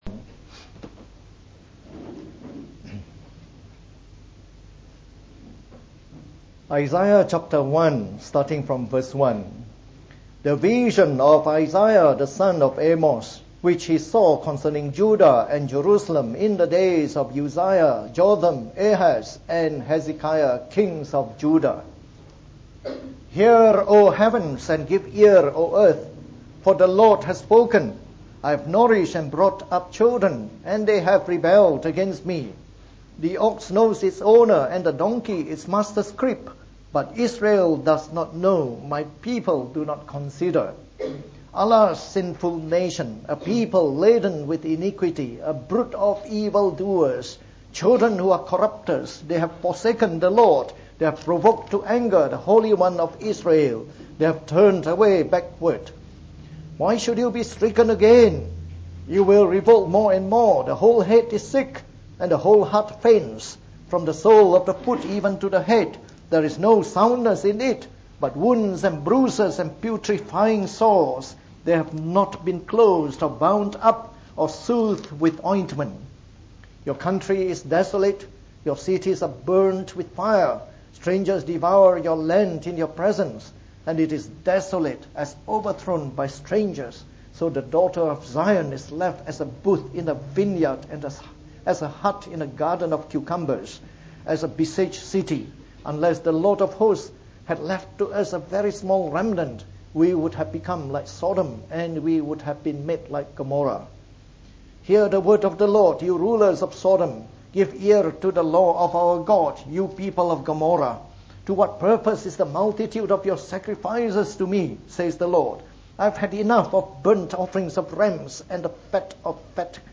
From our new series on the book of Isaiah delivered in the Morning Service.